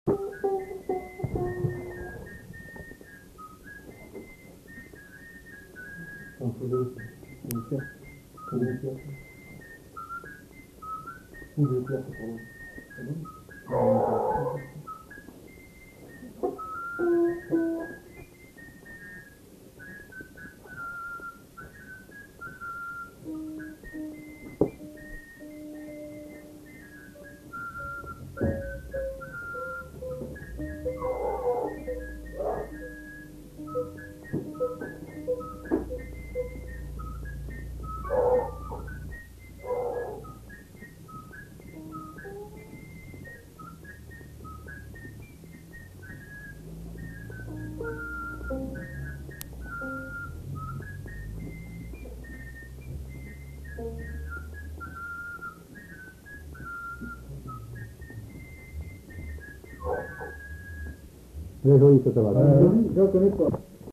Valse
Aire culturelle : Gabardan
Lieu : Estigarde
Genre : morceau instrumental
Instrument de musique : flûte de Pan
Danse : valse